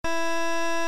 Wii Error
nintendo-wii-the-sound-of-death-online-audio-converter.mp3